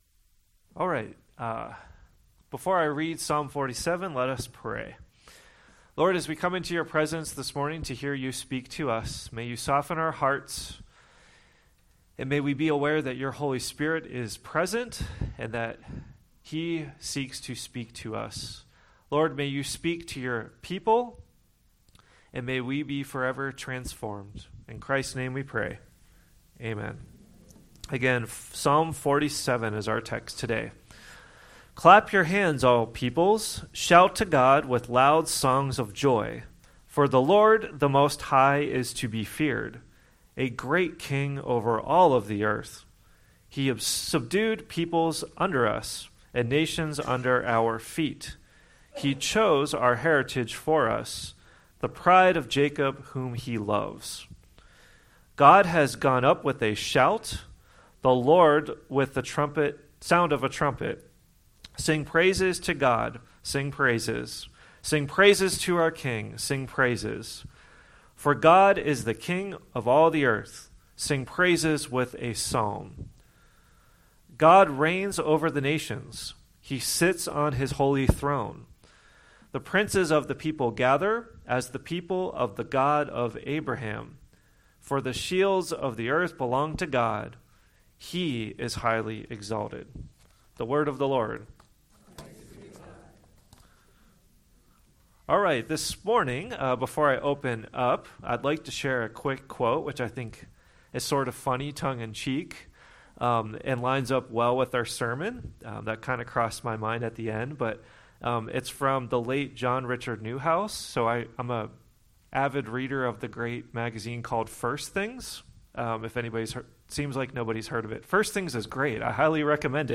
10:00AM In-Person Services
Sermon